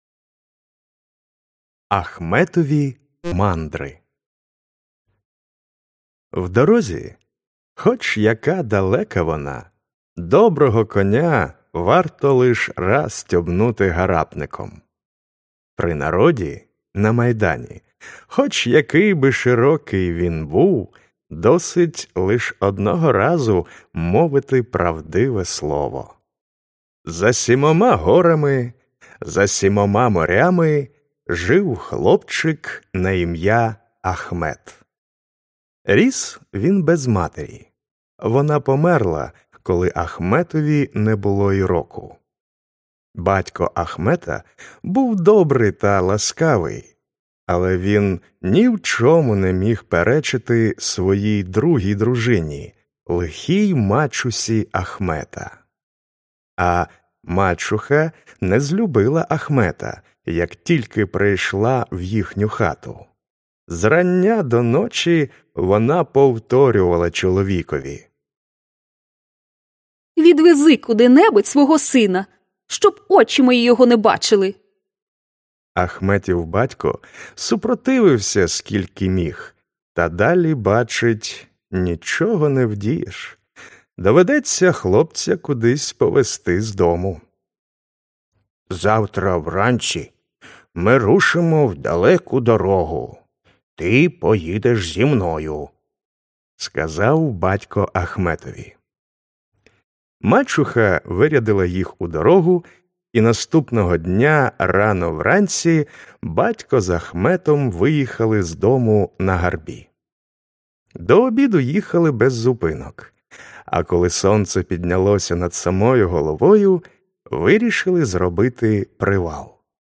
Аудіоказка Ахметові мандри